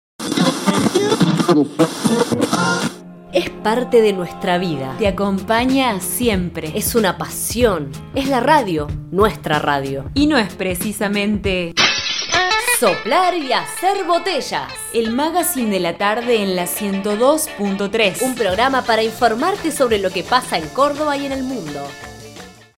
Locución
Artistica radial, spot de apertura de programa ficticio